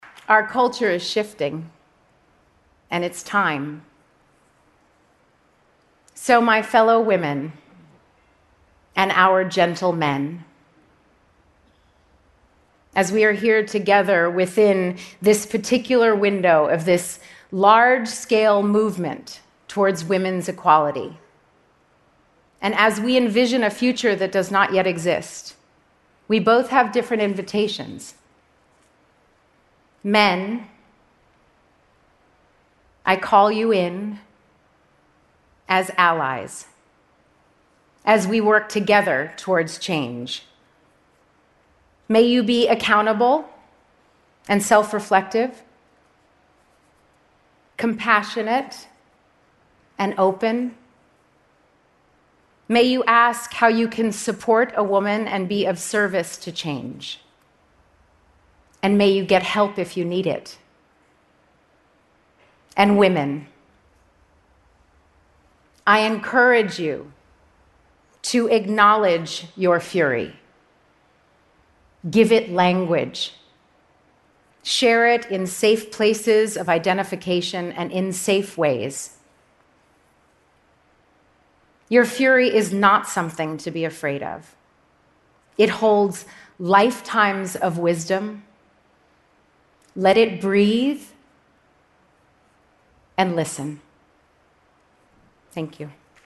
TED演讲:女人的愤怒蕴藏着一生的智慧(5) 听力文件下载—在线英语听力室